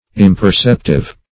Search Result for " imperceptive" : The Collaborative International Dictionary of English v.0.48: Imperceptive \Im`per*cep"tive\, a. Unable to perceive.